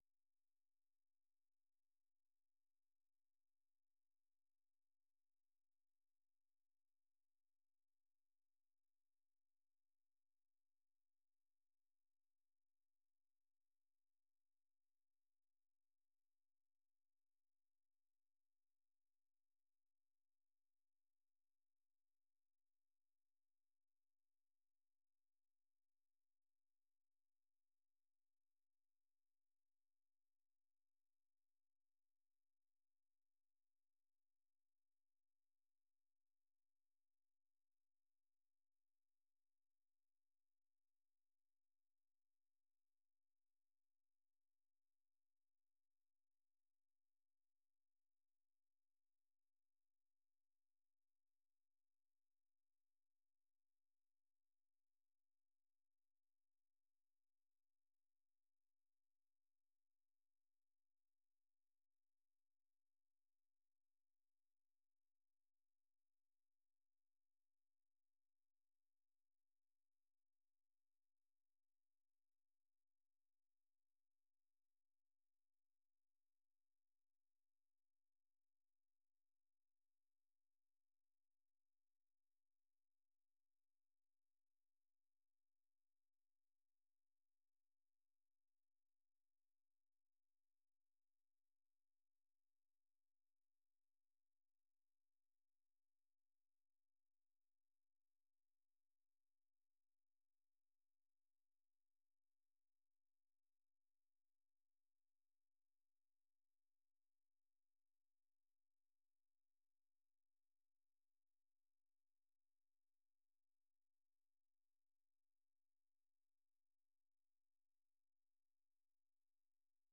생방송 여기는 워싱턴입니다 저녁
세계 뉴스와 함께 미국의 모든 것을 소개하는 '생방송 여기는 워싱턴입니다', 저녁 방송입니다.